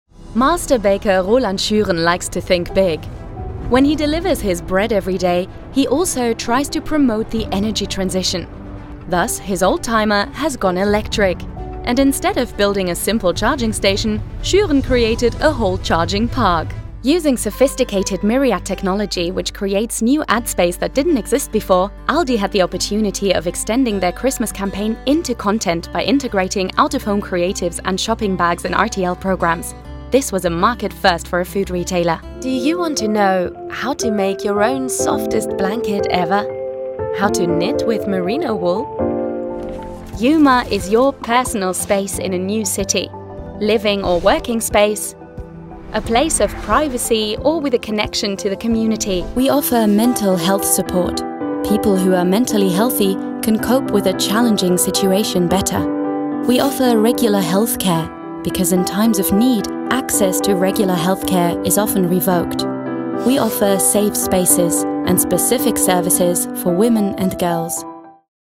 Vídeos explicativos
Mi voz es identificable, contemporánea y juvenil con un sonido cálido y texturizado.
Desde mi estudio hogareño con calidad de transmisión en Londres, he trabajado con cientos de clientes de todo el mundo, entregando un trabajo de primera calidad en alemán, inglés y francés.